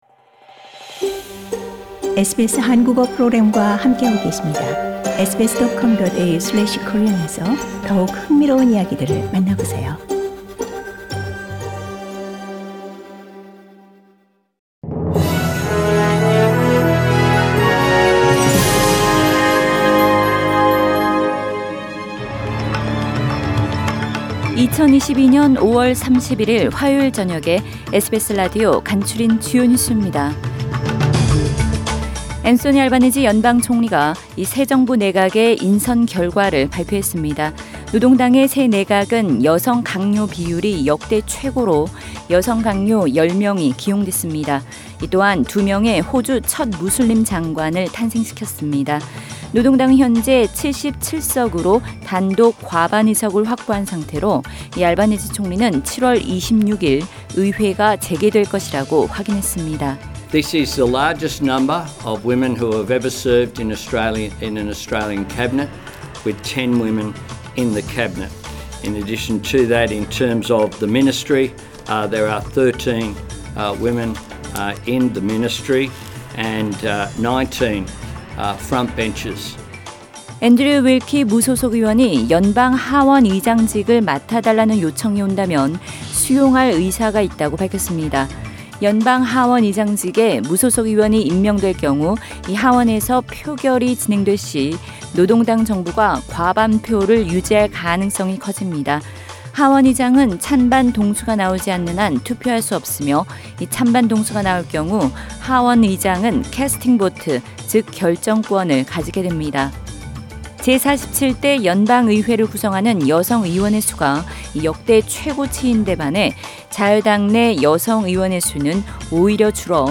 SBS 한국어 저녁 뉴스: 2022년 5월 31일 화요일